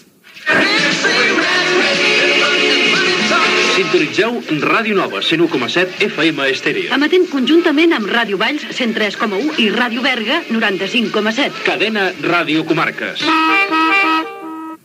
Identificació de les emissores connectades i de la cadena
FM